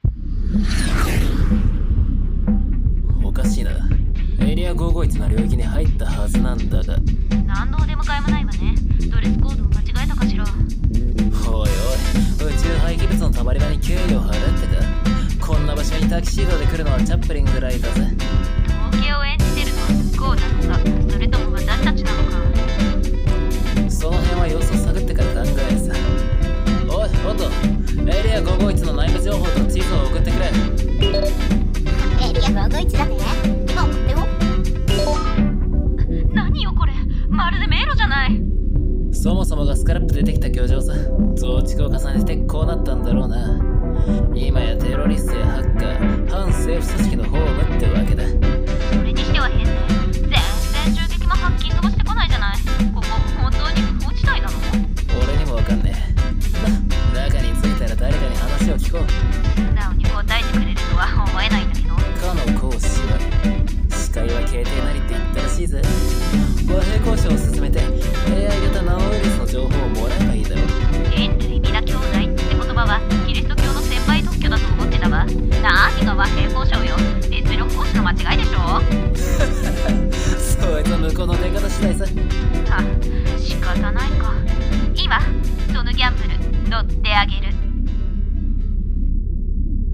【声劇】エリア551／BOUNTY SOUL 【2人声劇】